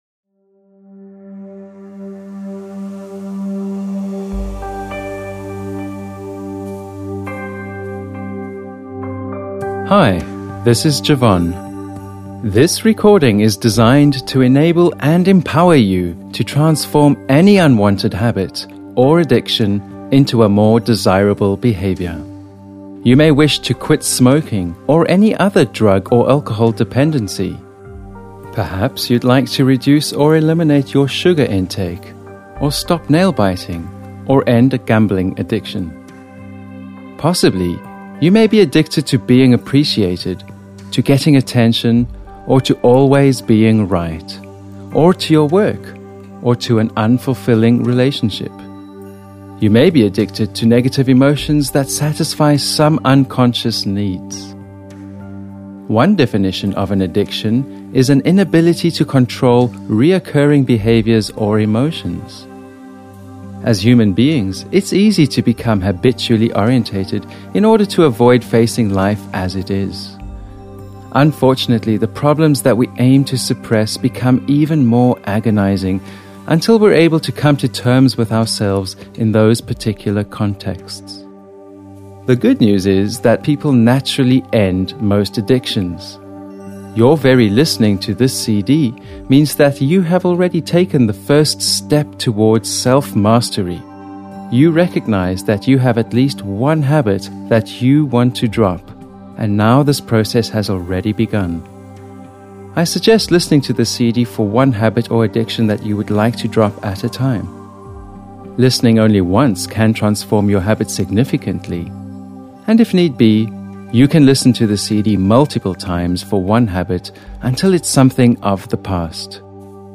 This soothing and comforting recording can help you drop any of them.
Beautiful calming music embedded with state of the art brain-stimulating audio technology, including inductive therapeutic voice techniques are skilfully used to create this profound and enjoyable listening experience.